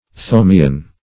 Search Result for " thomean" : The Collaborative International Dictionary of English v.0.48: Thomaean \Tho*mae"an\, Thomean \Tho*me"an\, n. (Eccl.
thomean.mp3